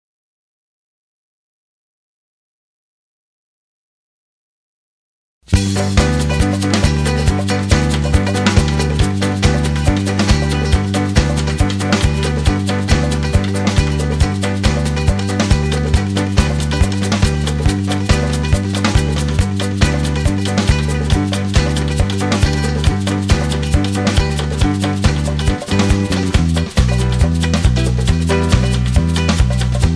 backing tracks
rock and roll
country music